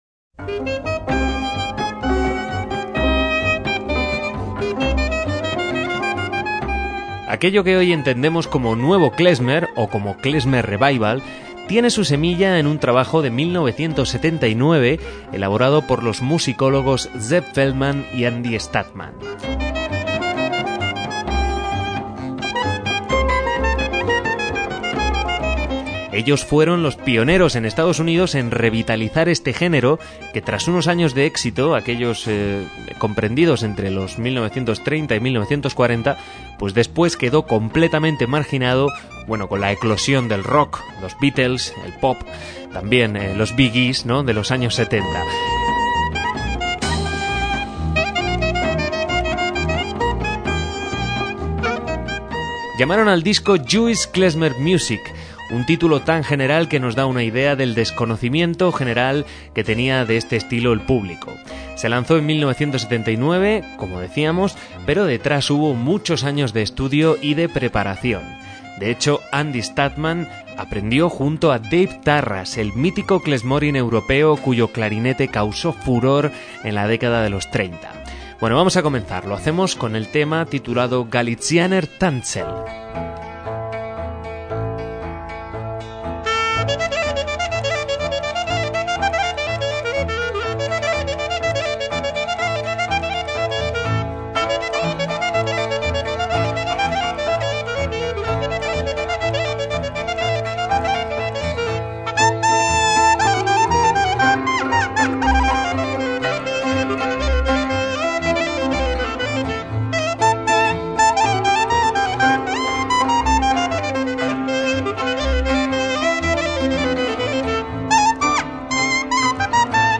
MÚSICA KLEZMER
clarinete y mandolina
tsimbl